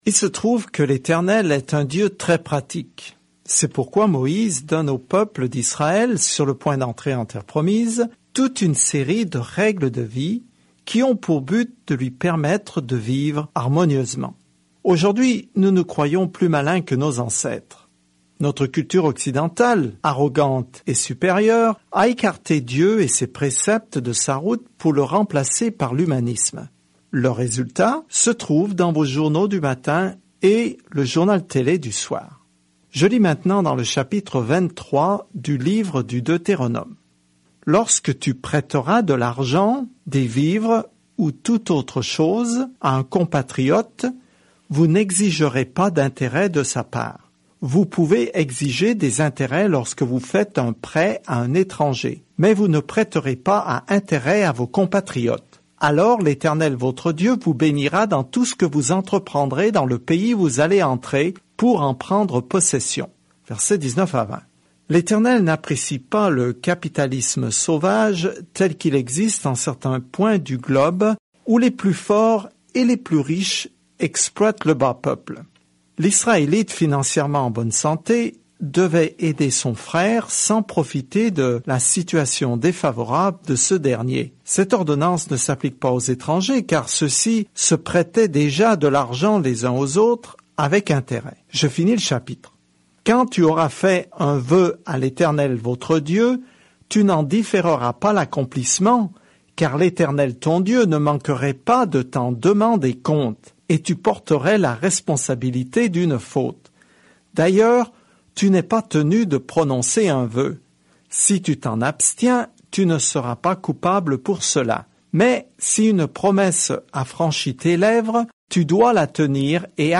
Écritures Deutéronome 23:18-25 Deutéronome 24 Deutéronome 25 Jour 13 Commencer ce plan Jour 15 À propos de ce plan Le Deutéronome résume la bonne loi de Dieu et enseigne que l’obéissance est notre réponse à son amour. Parcourez quotidiennement le Deutéronome en écoutant l’étude audio et en lisant certains versets de la parole de Dieu.